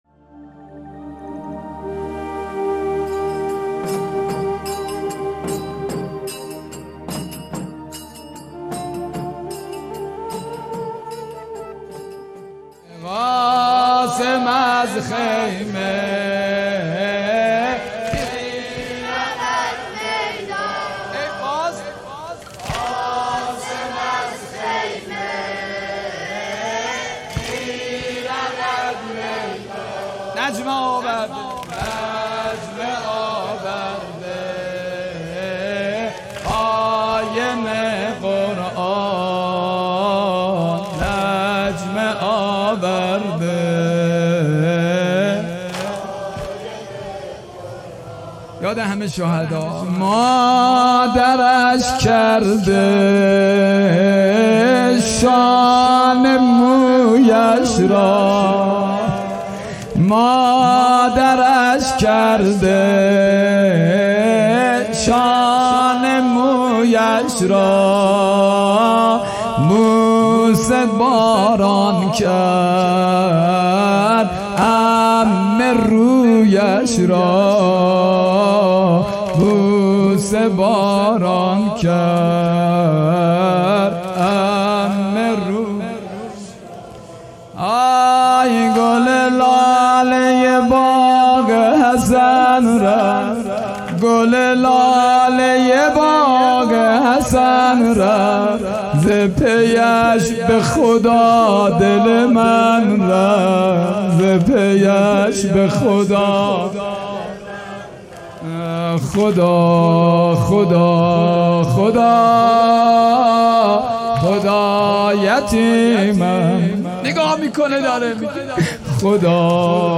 واحد | قاسم از خیمه میرود میدان
مداحی واحد
دهه اول - شب ششم محرم 1402 | هیأت بیت النبی (ص) _ قم